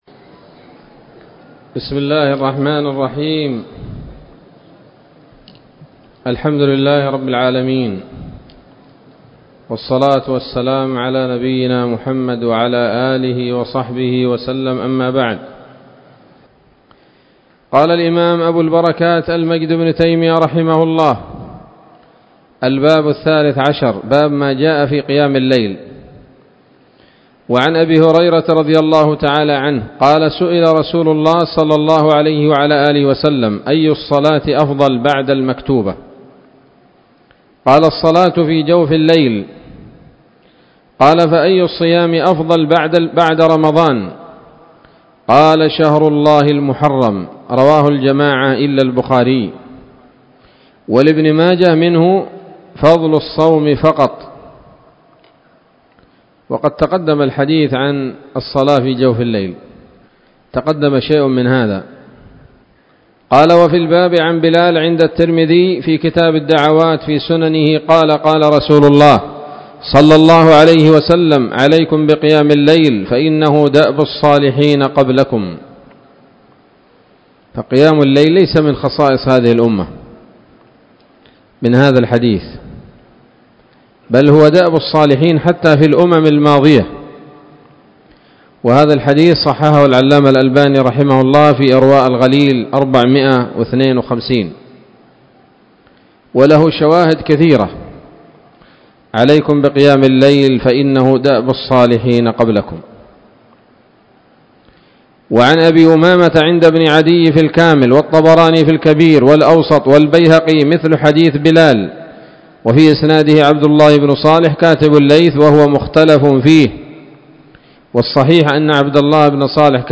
الدرس السادس والعشرون من ‌‌‌‌أَبْوَابُ صَلَاةِ التَّطَوُّعِ من نيل الأوطار